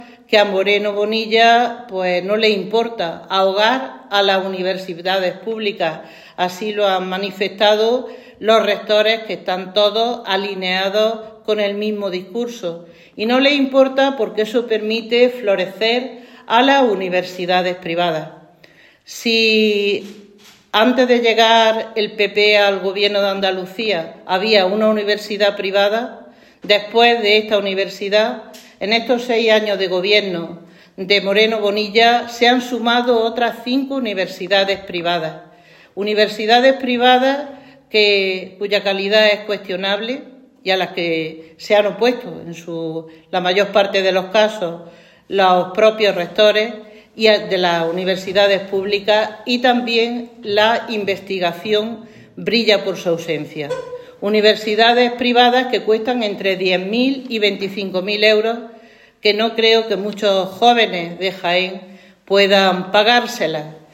En rueda de prensa
Cortes de sonido